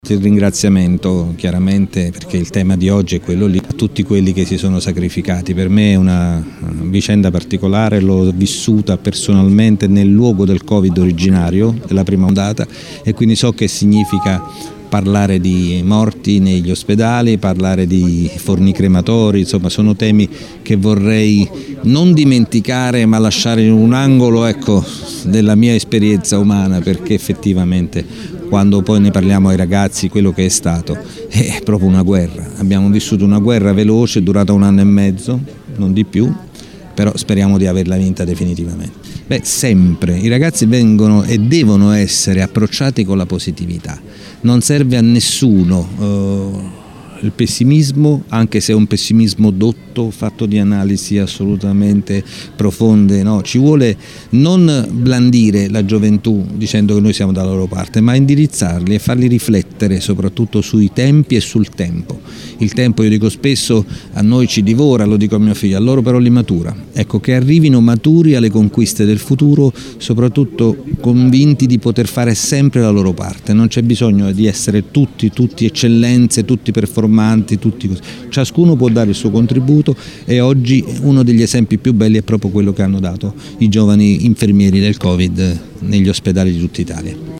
In chiusura, prima della consegna delle onorificenze al Merito della Repubblica Italiana a cittadini pontini che si sono distinti nel periodo della pandemia, il prefetto di Latina Maurizio Falco ha ricordato con commozione i giorni dell’esplosione della pandemia in nord Italia vissuti quando era prefetto di Piacenza: “In quei giorni morì il sindaco della città di Ferriere, porto con me sempre questo peso”.